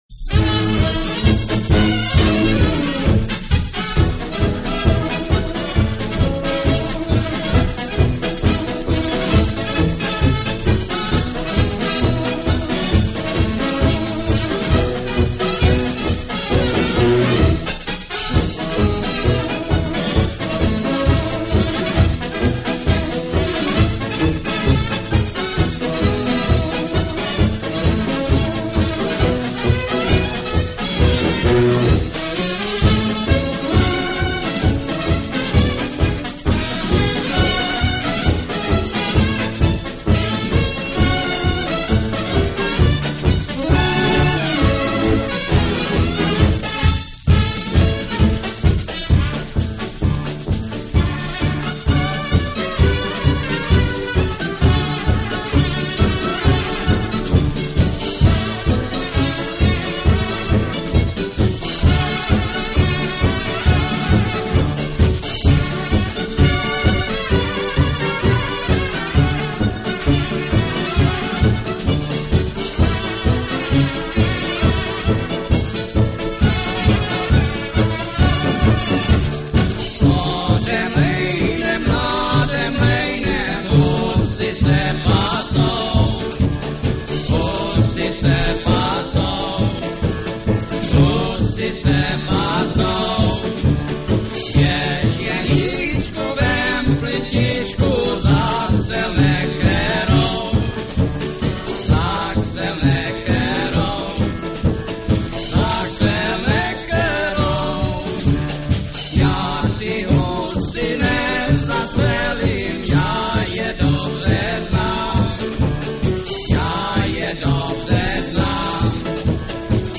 Commentary 12.